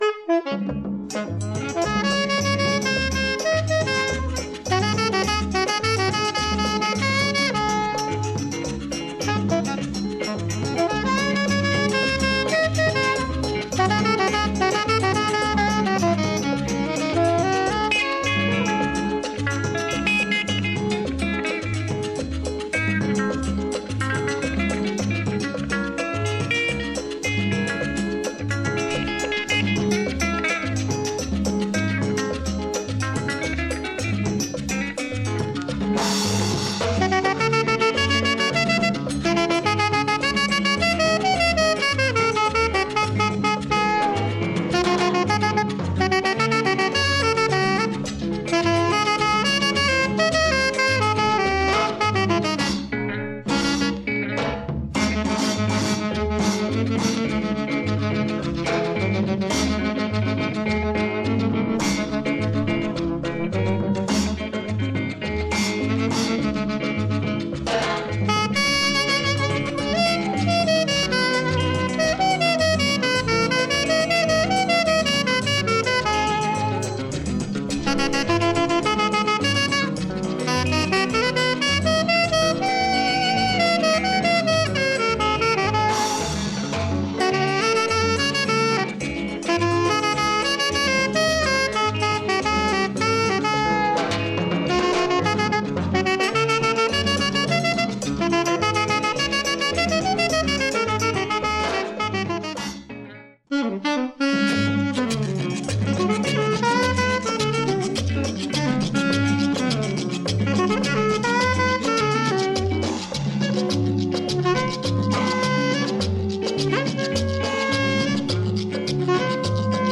Superb latin jazz and compas from Haiti